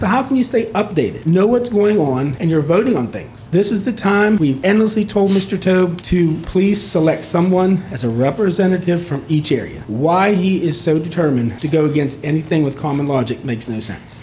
During last night’s Allegany County Commissioner meeting, the commissioners rejected the individuals the Allegany County Library System recently appointed to fill two board seats.
Lonaconing Mayor Jack Coburn said the board currently only visits those specific libraries when a board meeting is held there…